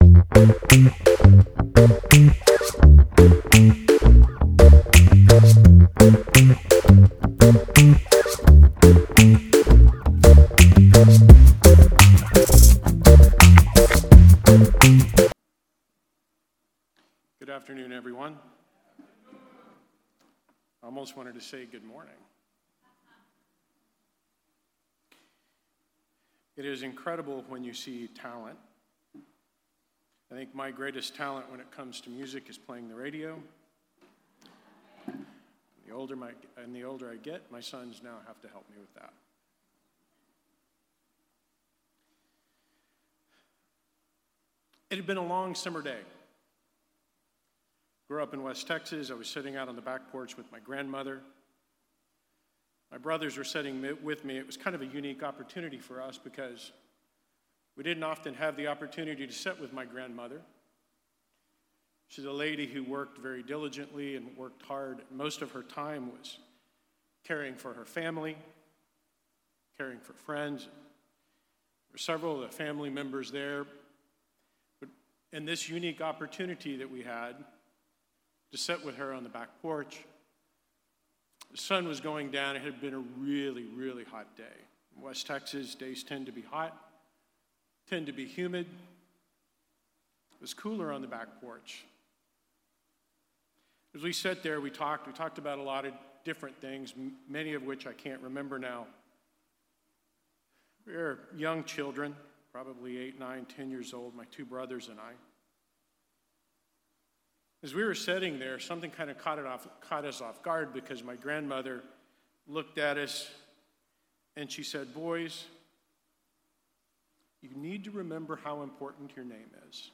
This sermon was given at the Montego Bay, Jamaica 2021 Feast site.